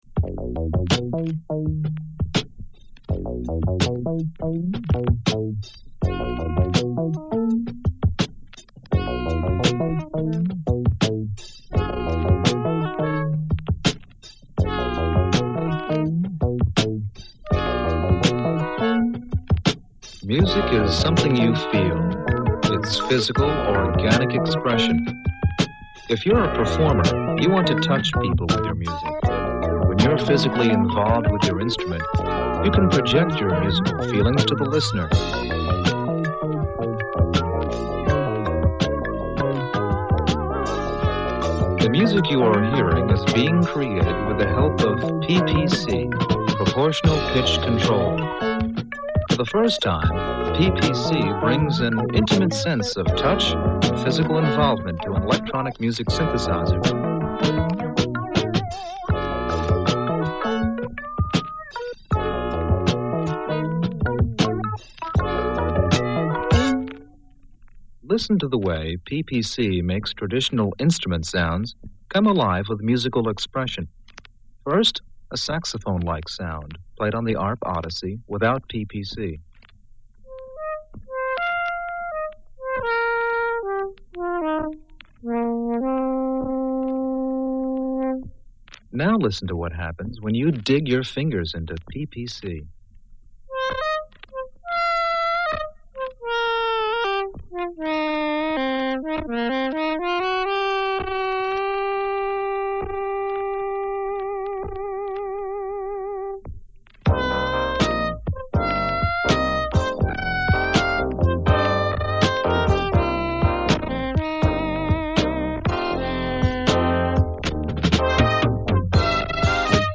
Floppy record originally mailed out in the September 1977 issue of Contemporary Keyboard magazine.
arp odyssey ppc demo.mp3